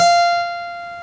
guitar note at approximately the same pitch.
guitar_same_note_as_opera.wav